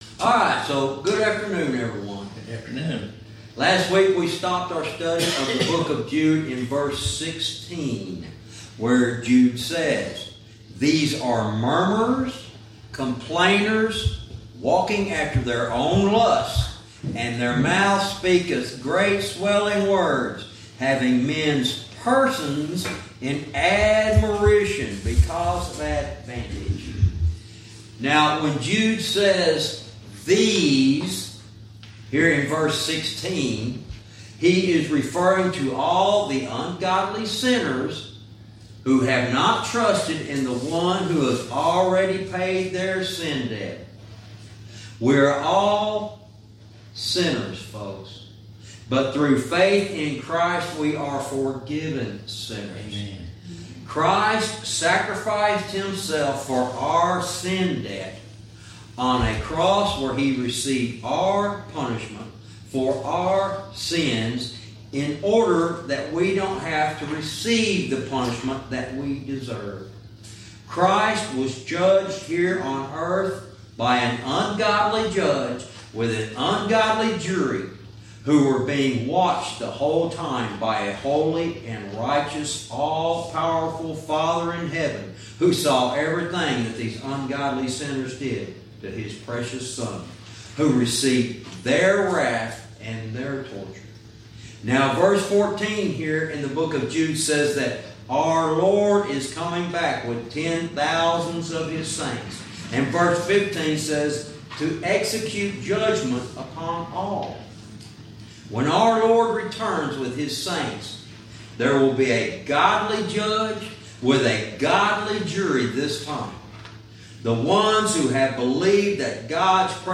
Verse by verse teaching - Jude lesson 71 verse 16